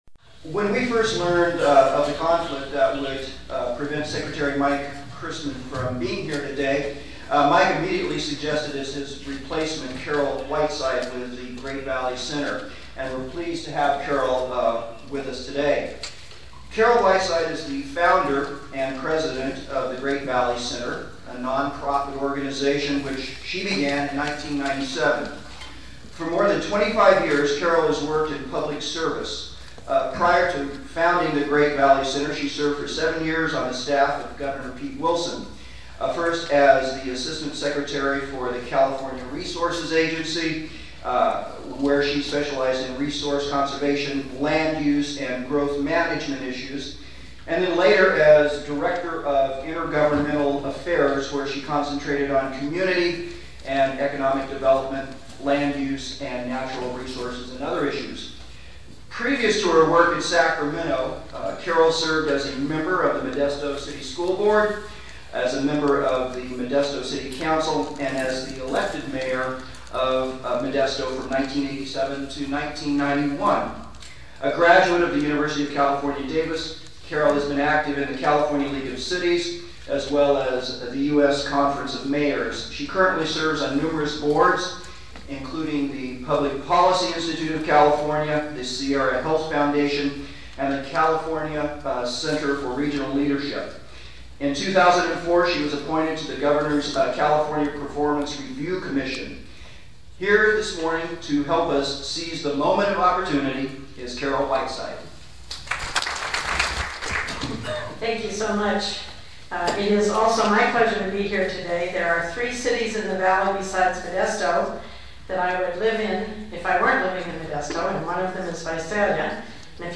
Concerns about land use and the future of the Southern San Joaquin Valley bubbled to the surface at the first Tulare County Land Use Public Forum on March 6, 2006 in Visalia.
Over 200 locals, mostly from the agribusiness and land development communities, attended the half-day event.